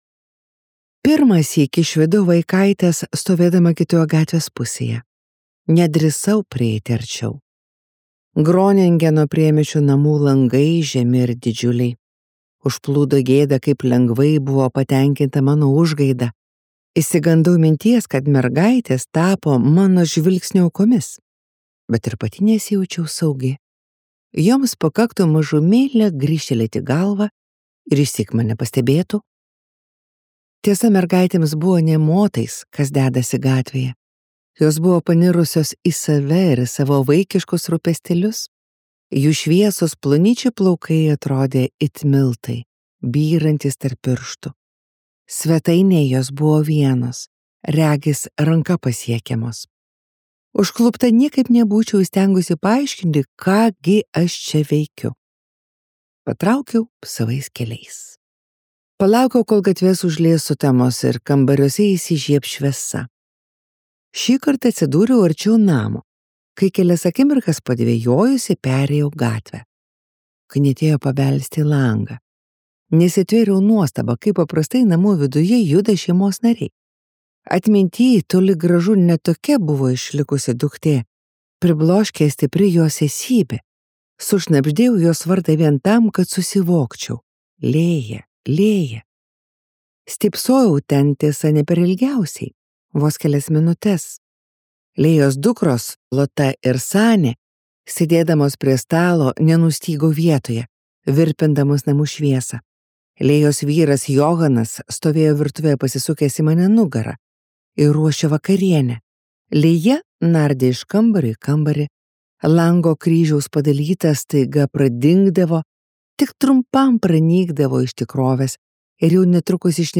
Hila Blum audioknyga „Kaip mylėti savo dukrą“ – tai įtaigi ir jautri istorija apie motinystės iššūkius, kaltės jausmą ir atitolimą. Knyga atskleidžia moters pastangas atkurti ryšį su dukra.